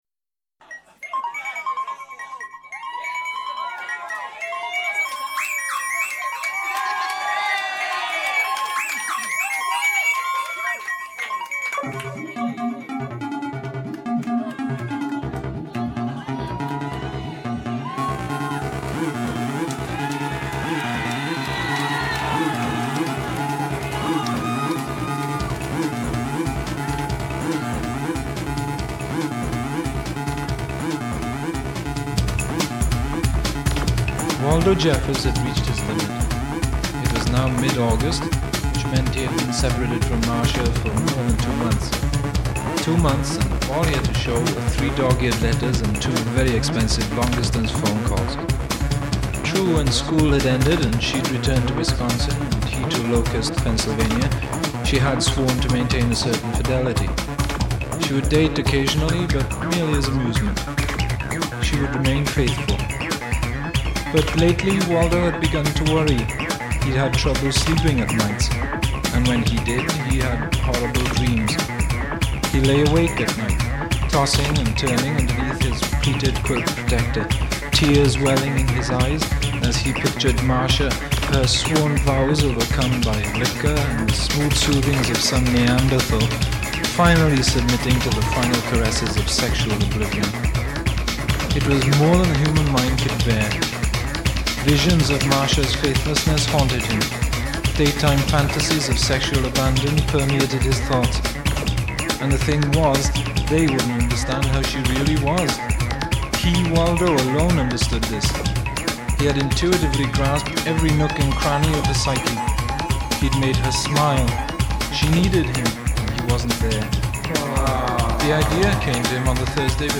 waldo jeffers' bass bins had reached their limit.
this sounds like an airplane landing on a minefield